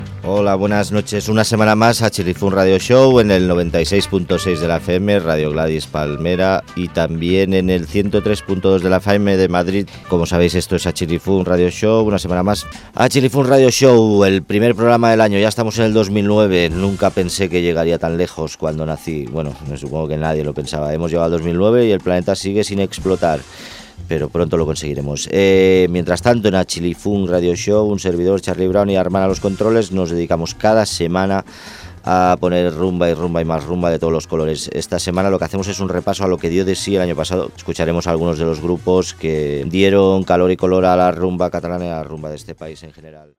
Indicatiu del programa i tema musical.
Musical